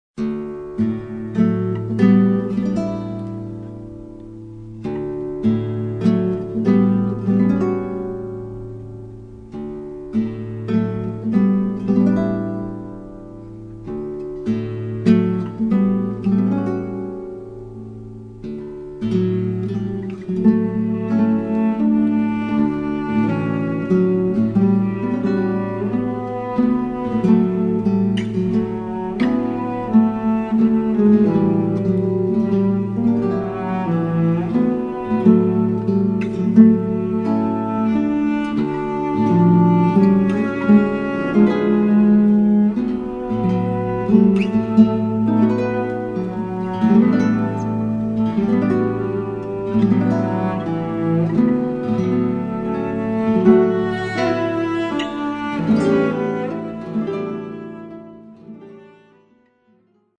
chitarra, chitarra battente, mandolino, mandola, percussioni